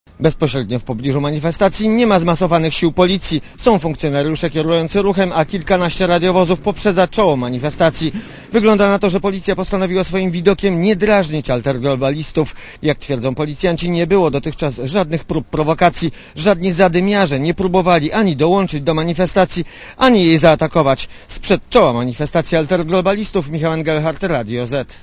Relacja reportera Radia ZET
W tle słychać syreny policyjne.
manifestacja.mp3